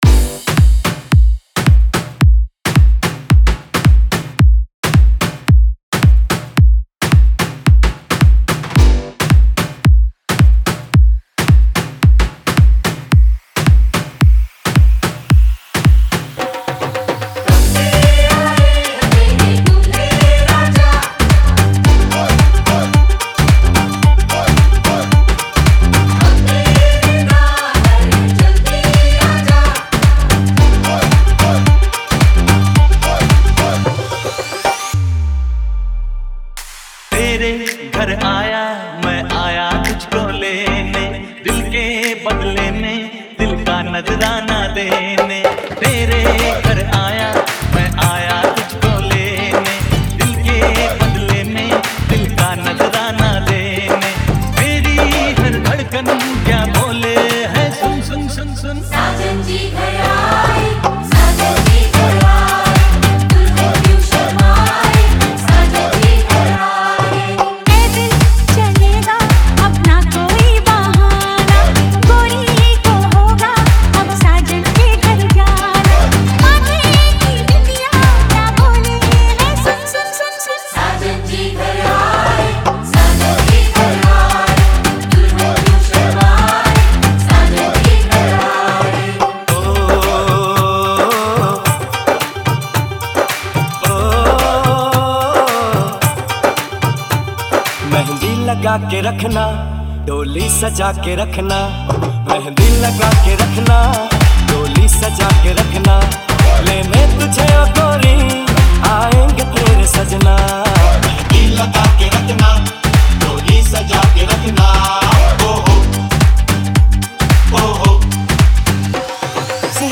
WEDDING MIX